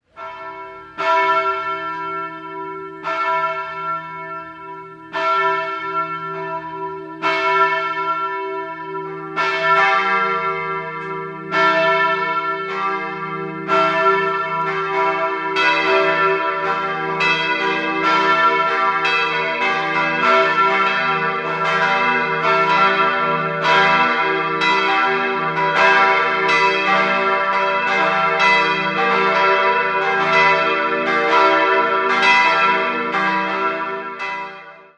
3-stimmiges E-Moll-Geläute: e'-g'-h'
Friedensglocke
Vaterunserglocke
Taufglocke